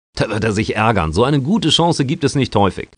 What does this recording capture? Kommentator: